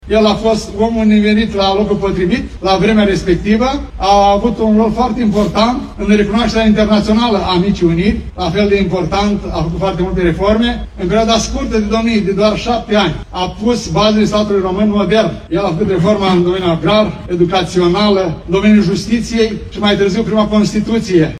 Astăzi, de Ziua Unirii Principatelor Române, oficialitățile locale și județene sucevene au inaugurat bustul lui ALEXANDRU IOAN CUZA, amplasat în Piața Drapelelor din centrul municipiului reședință.
În cuvântul său, primarul ION LUNGU a ținut să precizeze că ALEXANDRU IOAN CUZA “a avut un rol determinant în definirea statului modern român”.